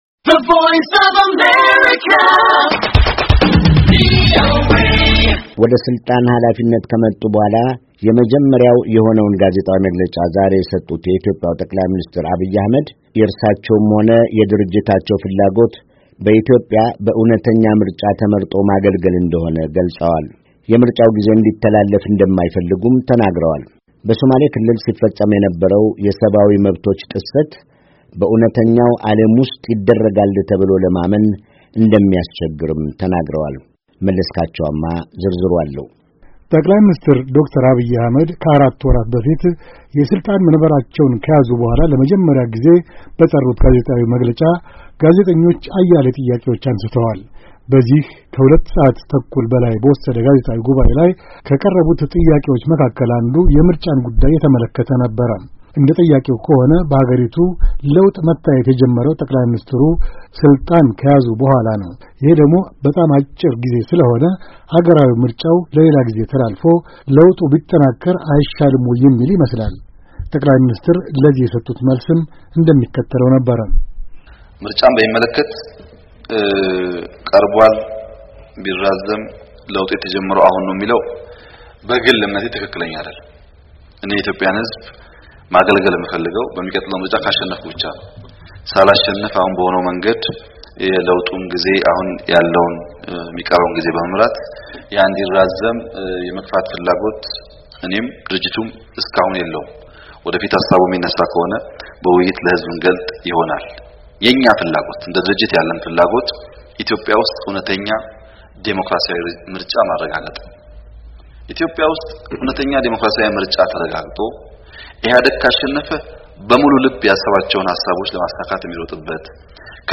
ጠ/ሚ አብይ አሕመድ ለጋዜጠኞች የሰጡት መግለጫ